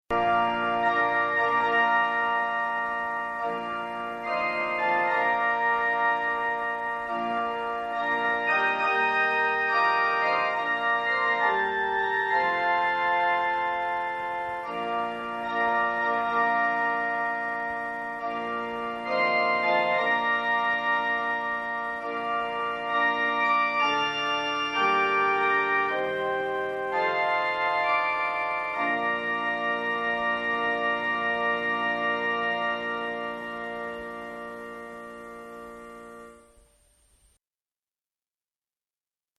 wedding bells Rendered